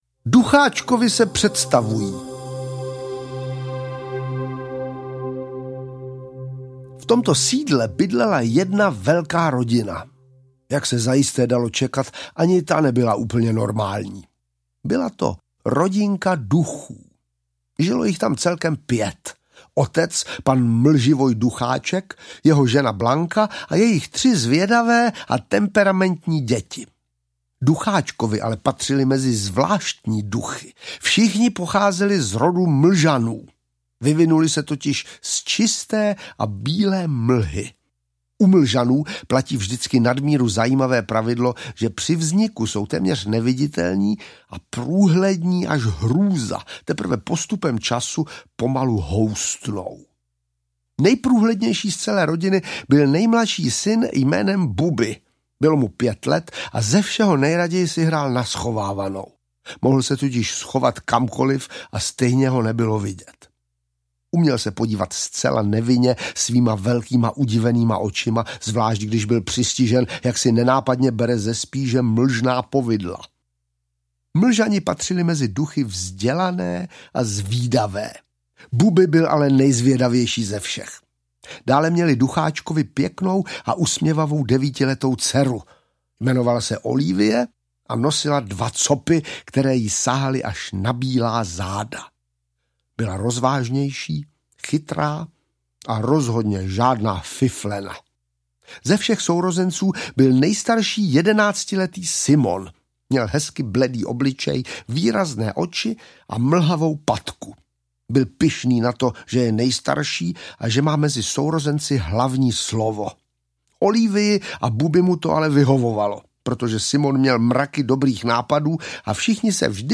Ducháčkovic rodina aneb Strašidla mezi námi audiokniha
Ukázka z knihy
• InterpretVáclav Vydra ml.